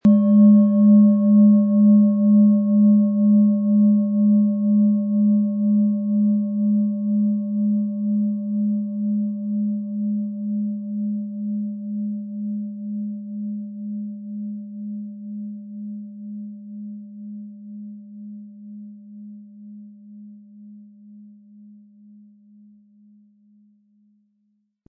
• Mittlerer Ton: Mond
PlanetentöneSaturn & Mond
MaterialBronze